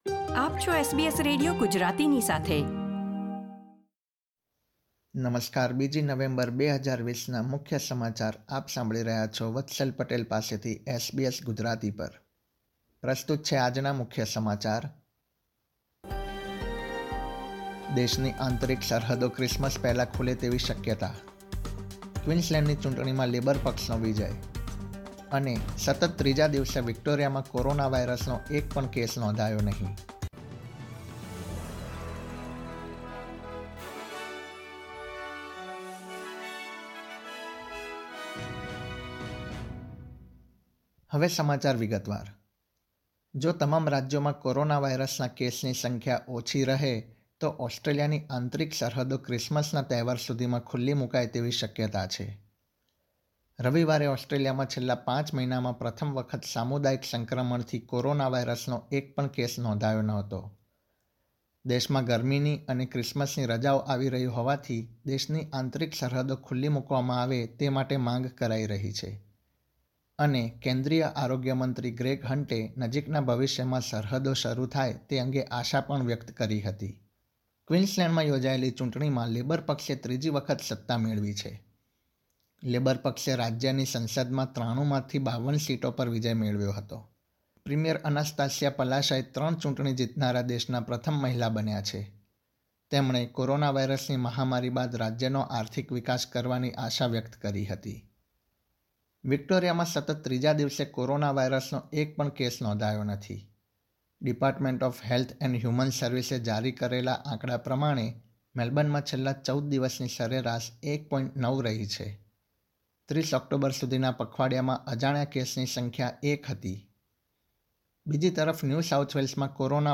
SBS Gujarati News Bulletin 2 November 2020
gujarati_0211_newsbulletin.mp3